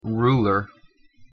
pronunciation-en-ruler.mp3